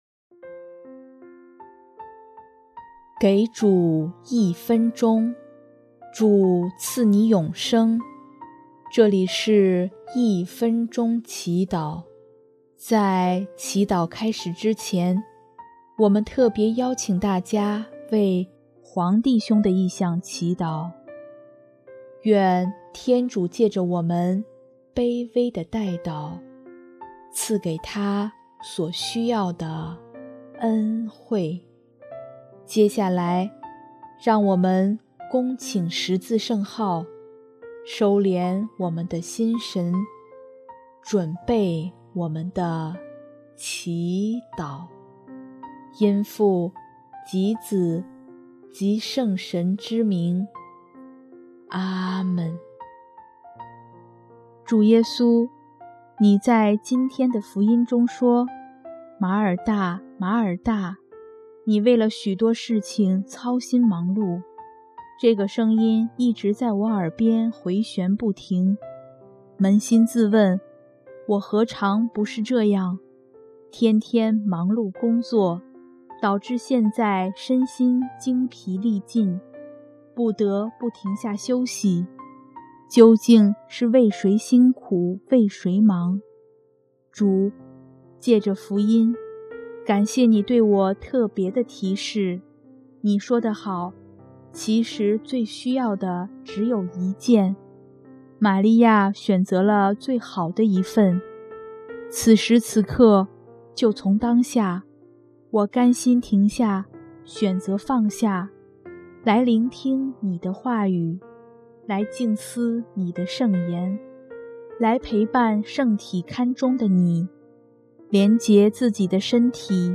首页 / 祈祷/ 一分钟祈祷